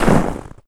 STEPS Snow, Run 28.wav